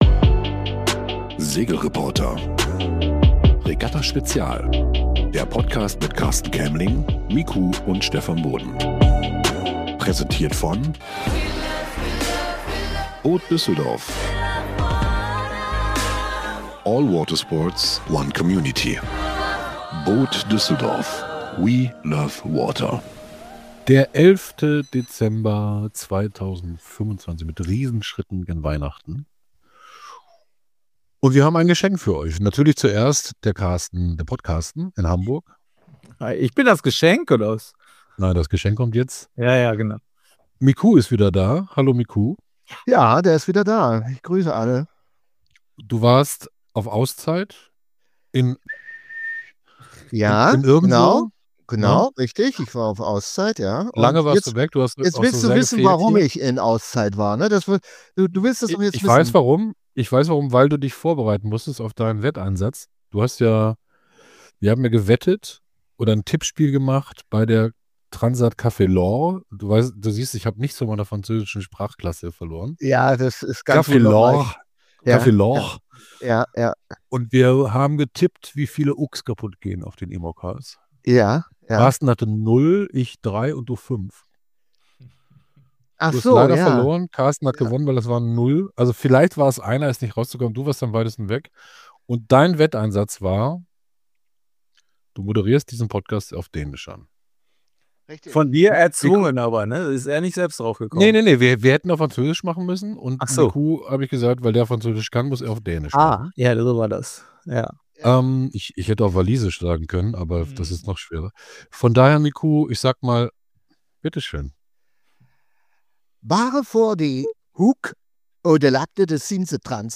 Hitzige Diskussion über die IMOCA-Szene ~ Der SegelReporter-Podcast Podcast